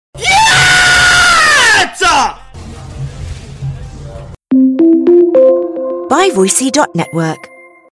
Gyatt Meme Sound Effect sound effects free download